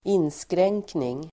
Uttal: [²'in:skreng:kning]